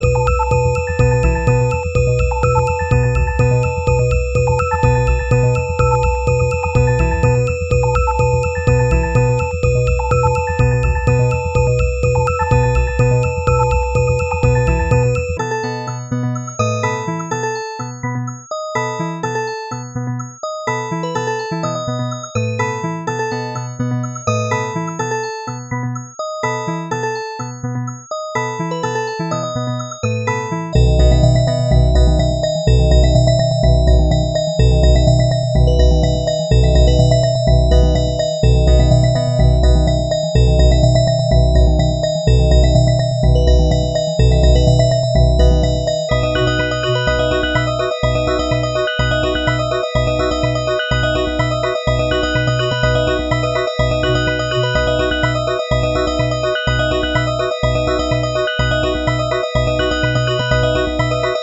Here are just four hand-picked loops at 340 generations (63k ratings): Or download from the "attachment" link below.